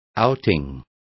Complete with pronunciation of the translation of outings.